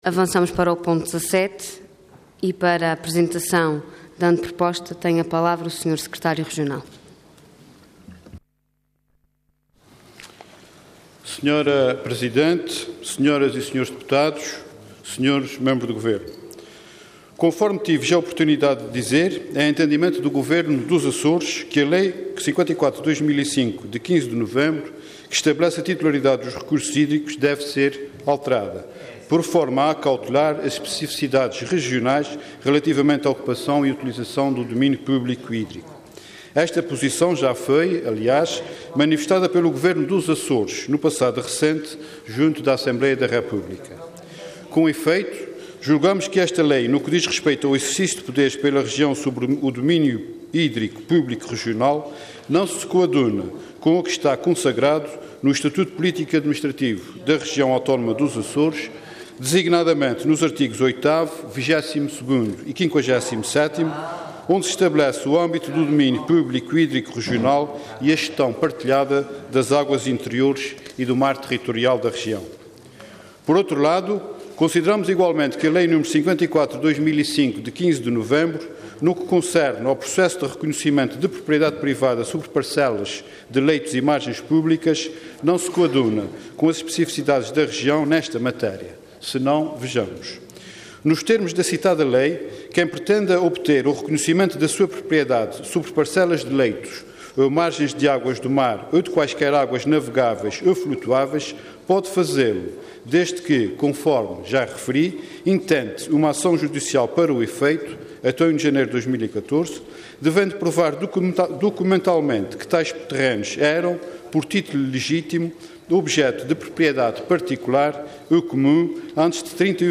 Detalhe de vídeo 18 de outubro de 2013 Download áudio Download vídeo Processo X Legislatura Altera a Lei n.º 54/2005, de 15 de novembro. Intervenção Anteproposta de Lei Orador Luís Neto de Viveiros Cargo Secretário Regional dos Recursos Naturais Entidade Governo